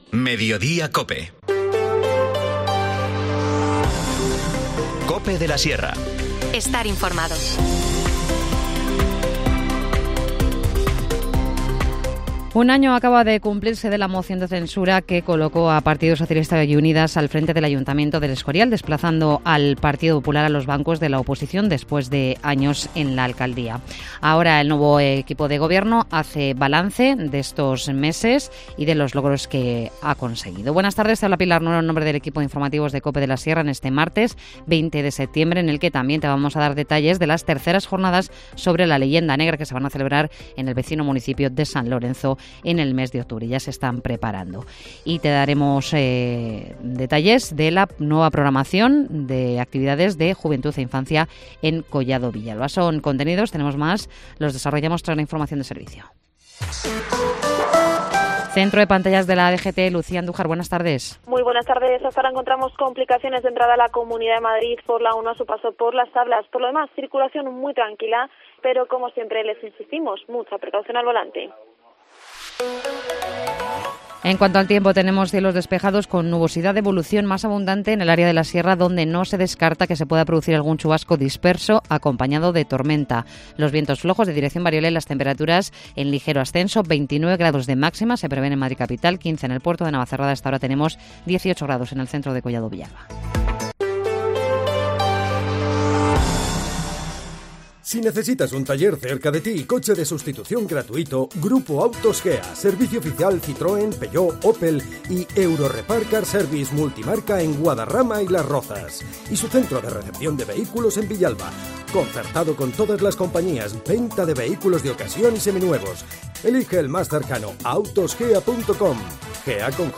Informativo Mediodía 20 septiembre